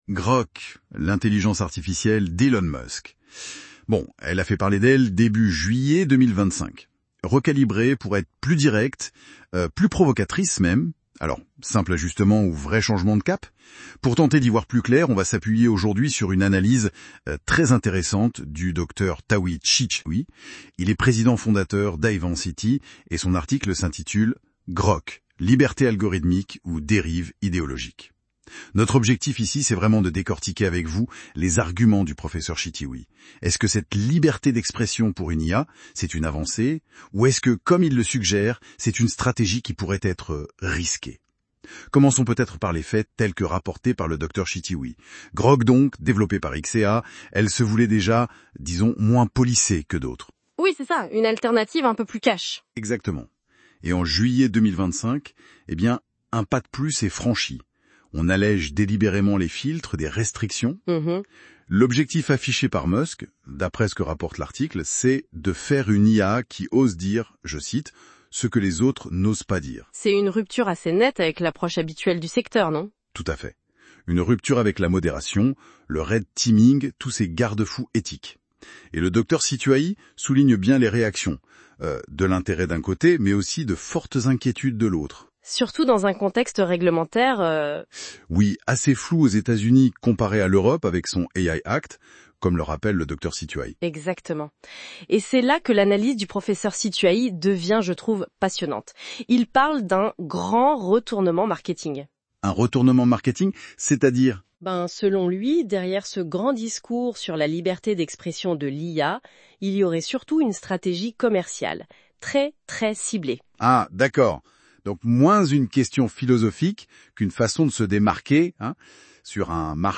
Lire le résumé audio généré par IA